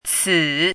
chinese-voice - 汉字语音库
ci3.mp3